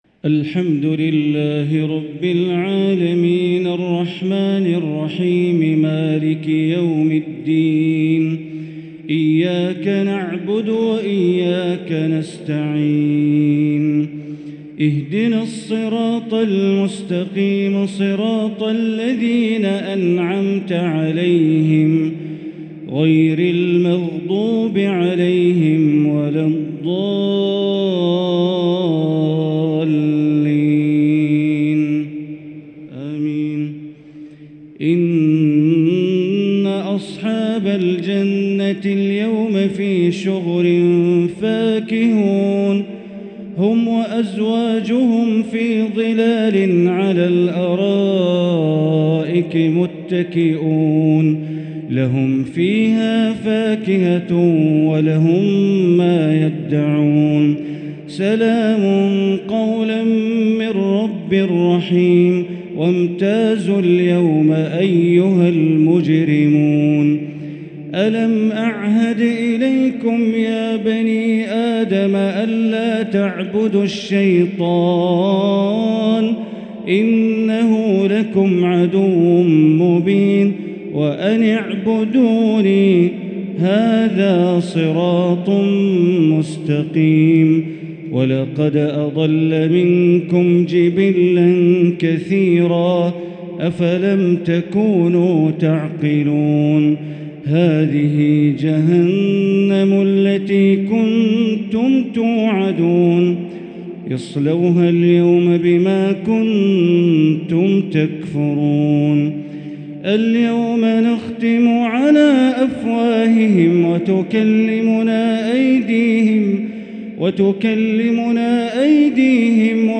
تراويح ليلة 25 رمضان 1444هـ من سورتي يس (55-83) و الصافات (1-113) | taraweeh 25 st night Ramadan 1444H Surah Yaseen and As-Saaffaat > تراويح الحرم المكي عام 1444 🕋 > التراويح - تلاوات الحرمين